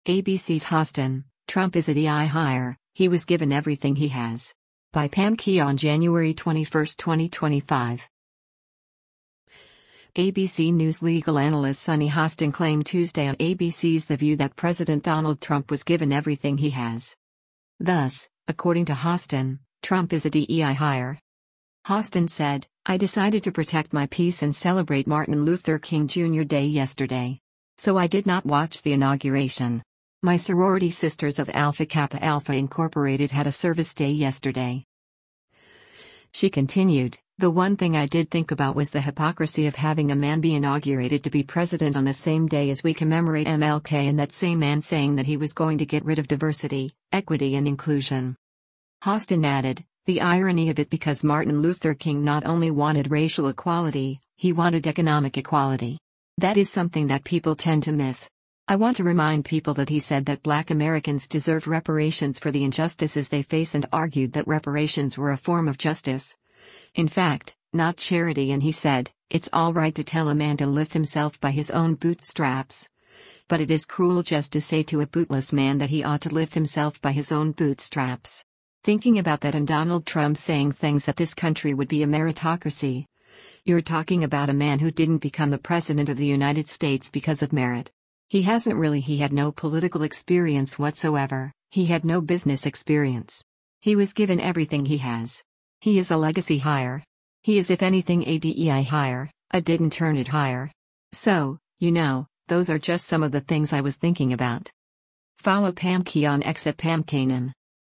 ABC News legal analyst Sunny Hostin claimed Tuesday on ABC’s “The View” that President Donald Trump “was given everything he has.”